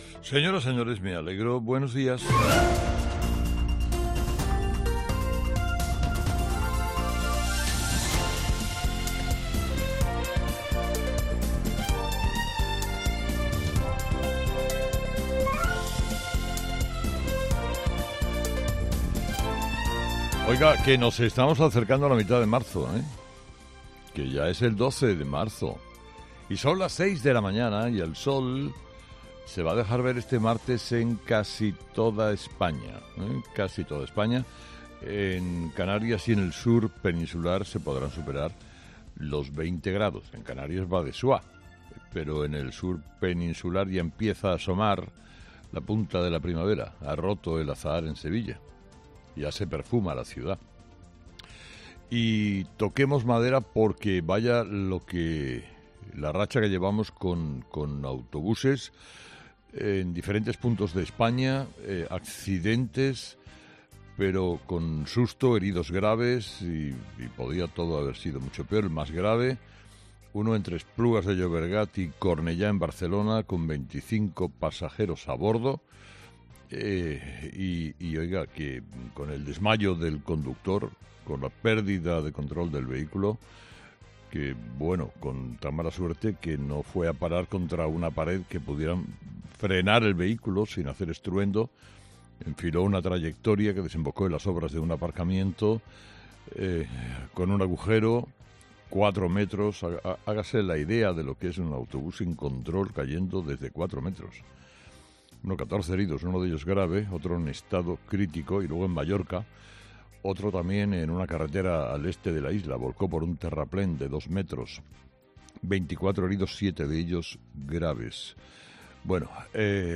Carlos Herrera, director y presentador de 'Herrera en COPE', comienza el programa de este martes analizando las principales claves de la jornada que pasan, entre otras cosas, por Pedro Sánchez en el homenaje a las víctimas del 11-M.